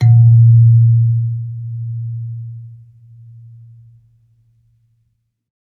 kalimba_bass
kalimba_bass-A#1-pp.wav